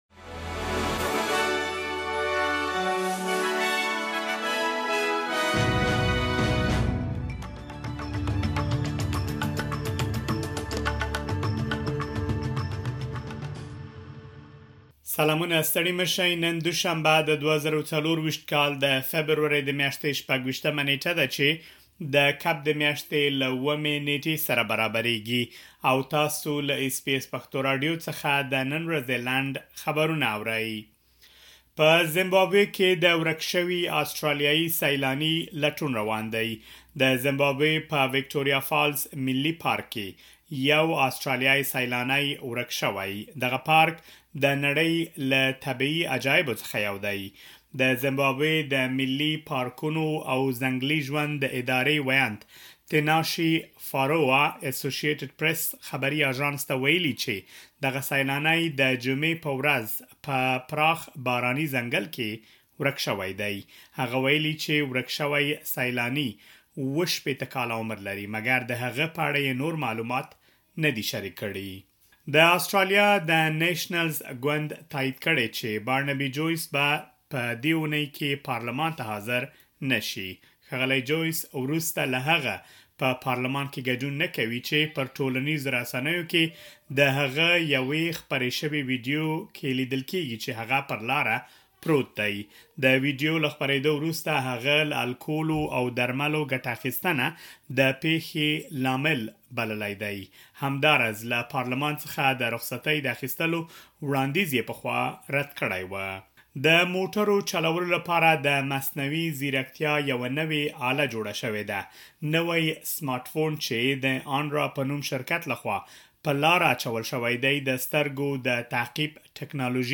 د اس بي اس پښتو راډیو د نن ورځې لنډ خبرونه |۲۶ فبروري ۲۰۲۴